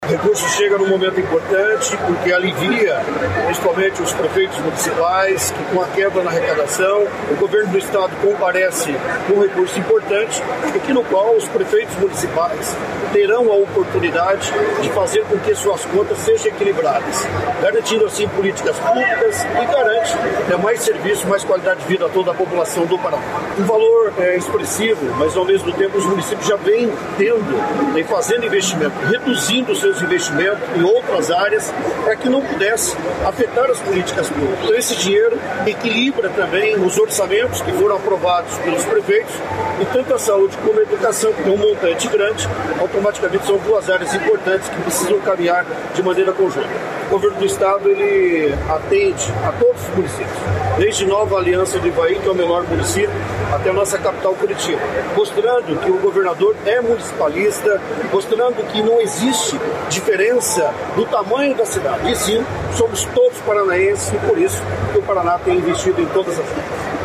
Sonora do presidente da AMP, Edimar Santos, sobre o repasse de R$ 455 milhões para os 399 municípios do Paraná